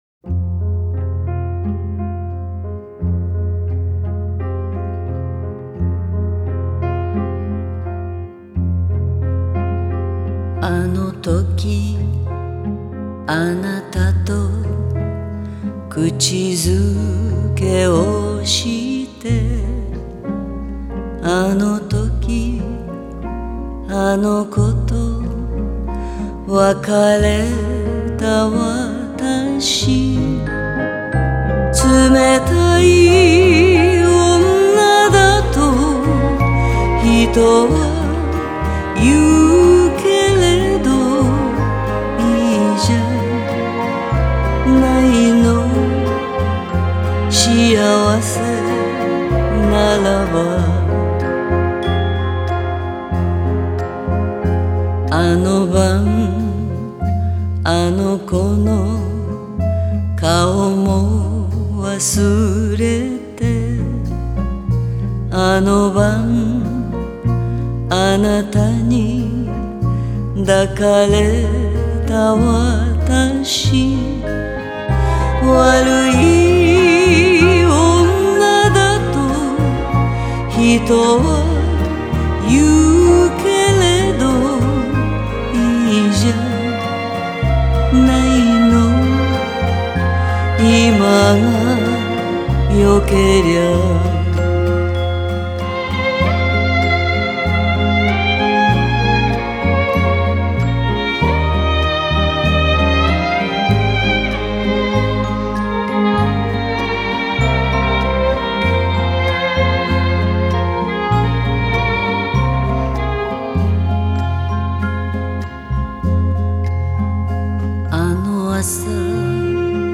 ジャンル: Jazz